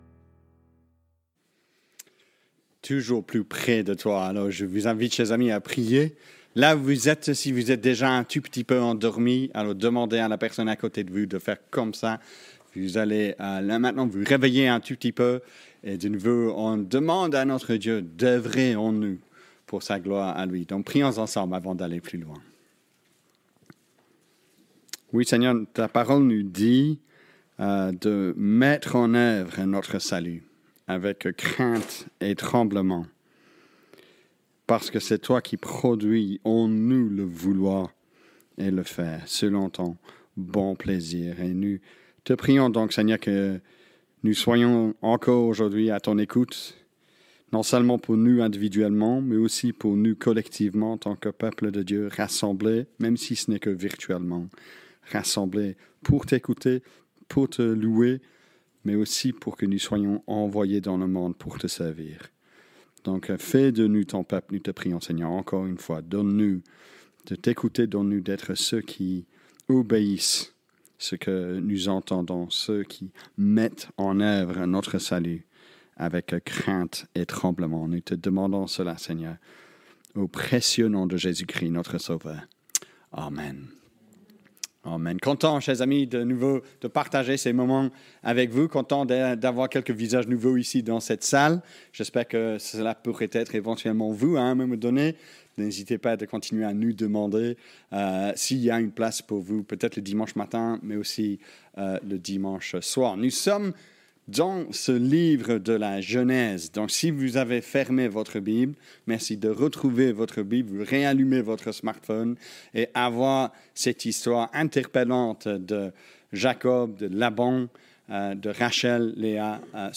Culte-du-7-février-2020-EPE-BruxellesWoluwe.mp3